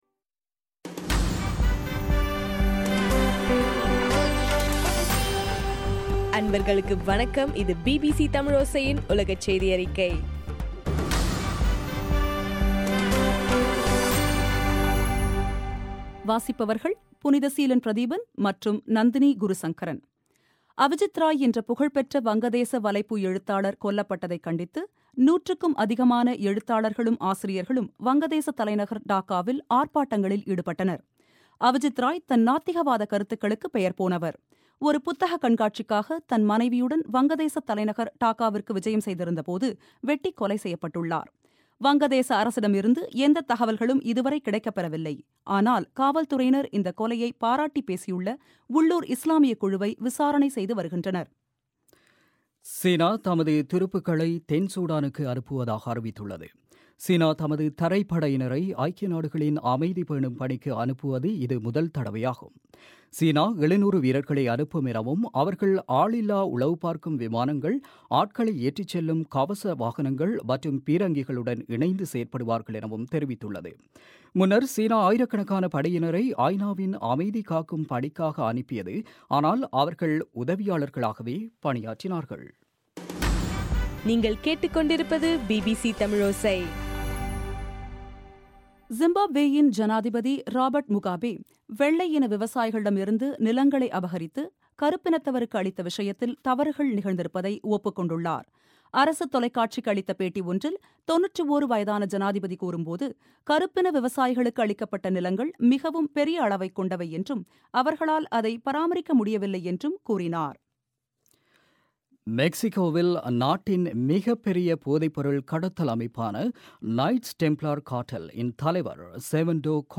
தமிழோசையின் உலகச் செய்தியறிக்கை